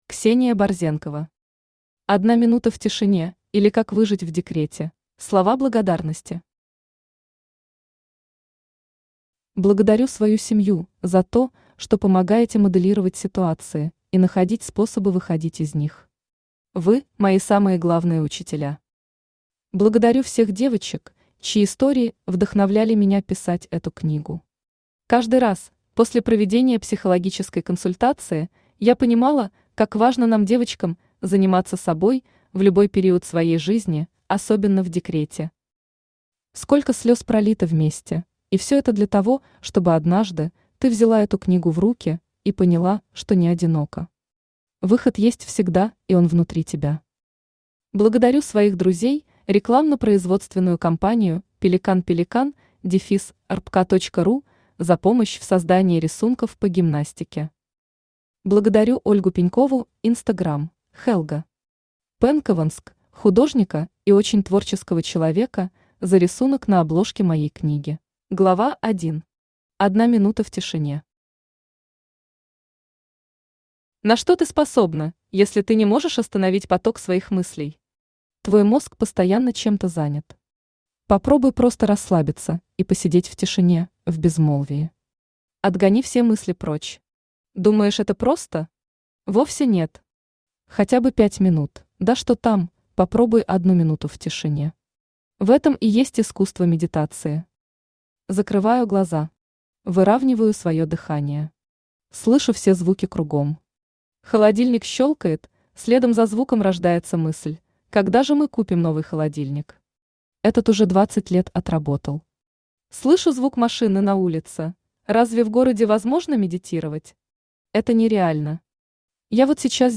Автор Ксения Николаевна Борзенкова Читает аудиокнигу Авточтец ЛитРес.